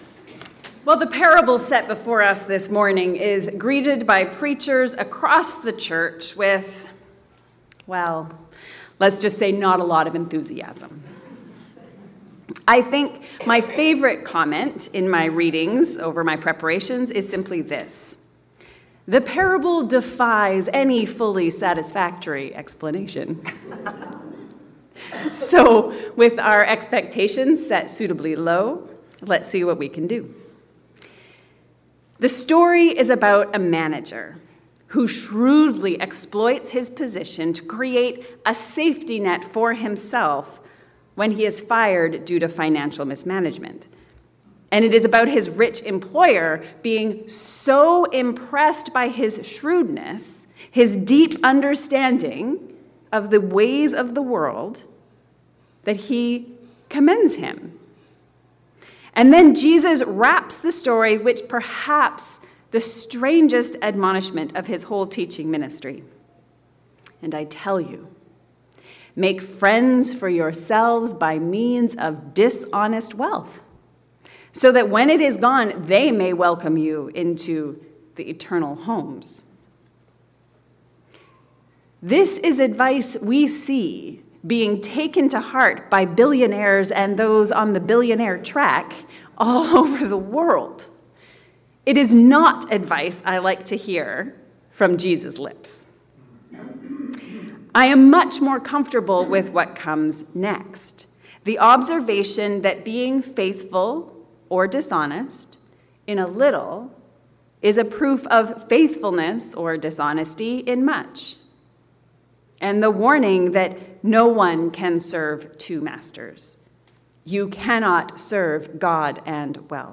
The parable of the dishonest – but single-minded – manager. A sermon on Luke 16:1-13